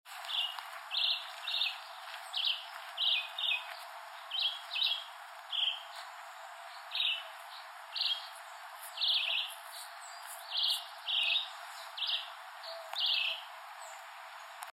Juruviara (Vireo chivi)
Nome em Inglês: Chivi Vireo
Fase da vida: Adulto
Localidade ou área protegida: Reserva Ecológica Costanera Sur (RECS)
Condição: Selvagem
Certeza: Gravado Vocal